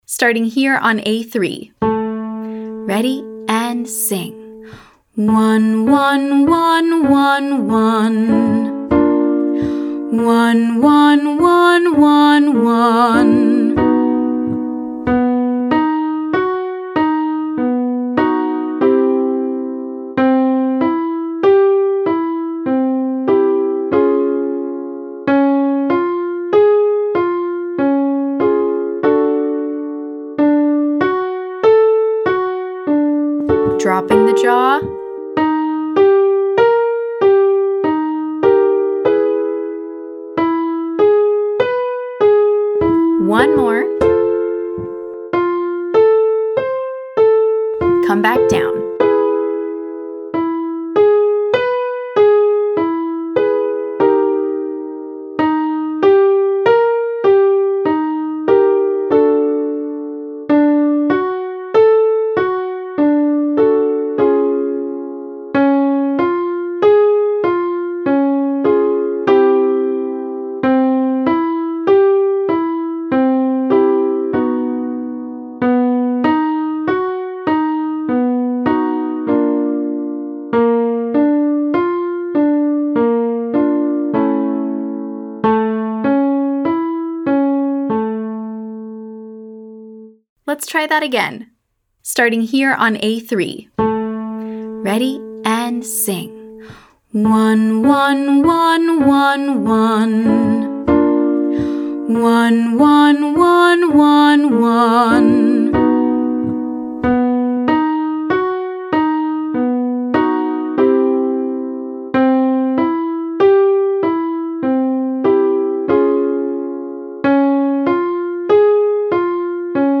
Exercise 1: ONE/WUH 13531